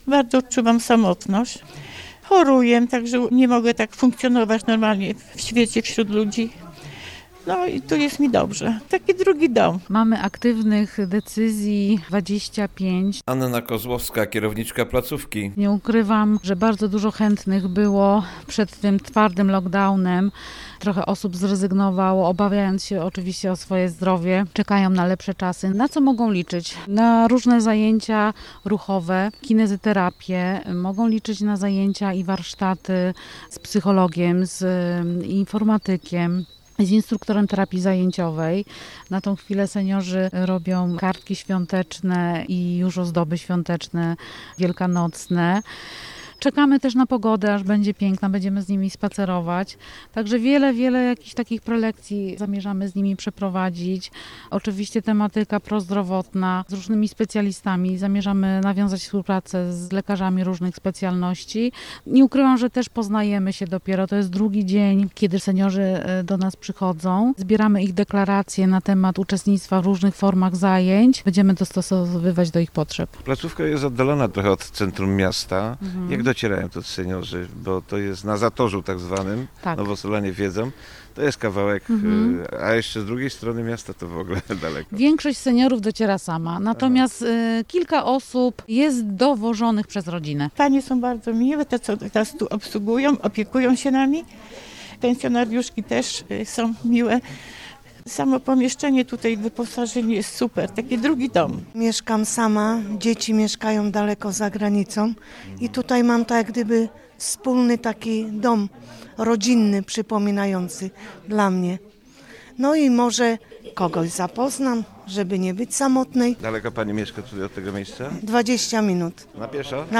Pomimo tego, że zajęcia trwają dopiero od wczoraj, uczestnicy są bardzo zadowoleni. Relacja